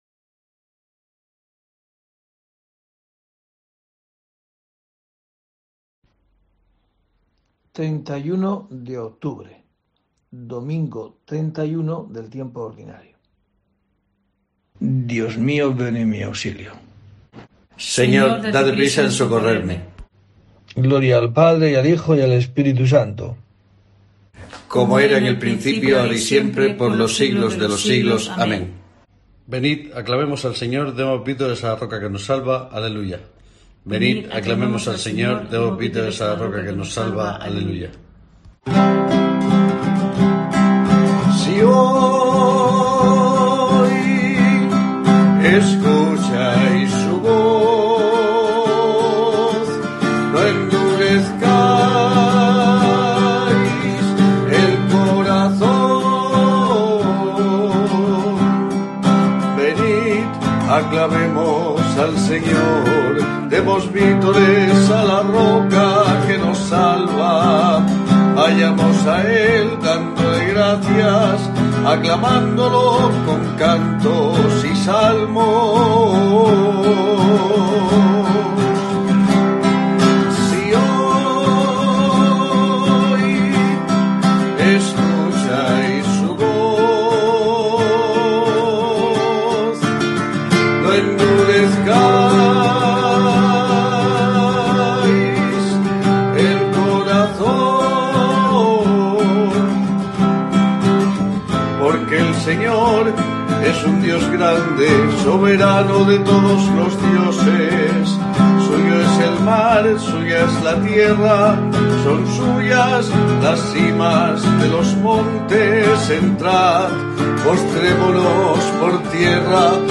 31 de octubre: COPE te trae el rezo diario de los Laudes para acompañarte